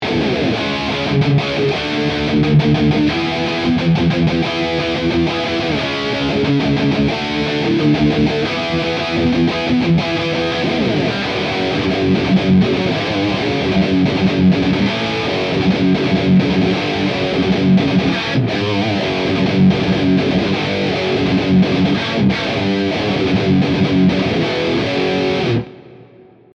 Tutte le clip audio, sono state registrate con testata a Marshall JCM800 sul canale Low e cassa 2×12 equipaggiata con altoparlanti Celestion Creamback, impostata su un suono estremamente clean.
Chitarra: Gibson Les Paul (pickup al ponte)
Mode: Lead 1
Gain: 7/10